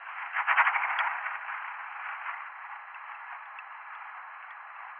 Tremor
“rumblings" from a slow release of acoustic/seismic energy and can sound, for example, like a train darting over train tracks. Here are a couple of examples of how tremor signals can sound.